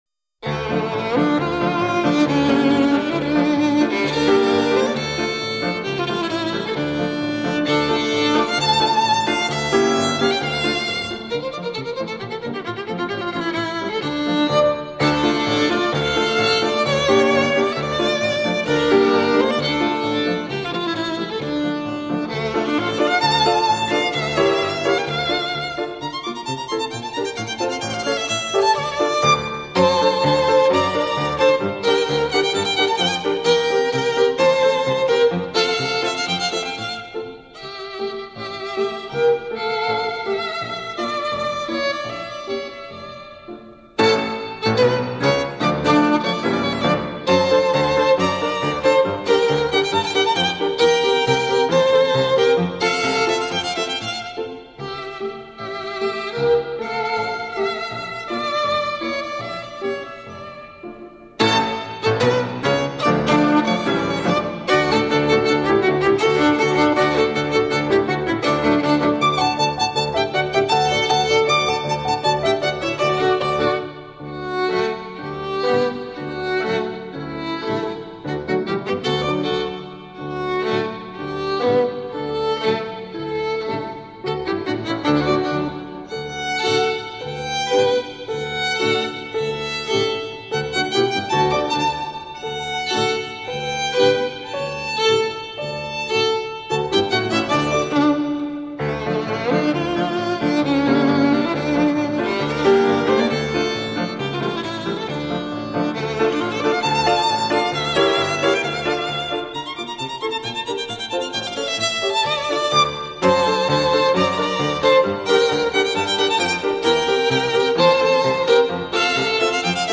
那些变幻的螺旋的旋律中，分弓奏出的重复，缺因了细微的强弱，我从不觉得单调。
层次又是如此分明，而欢畅又忧伤的感情，仿佛不费吹灰之力，让人迷醉。